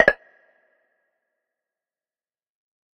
menu-play-click.ogg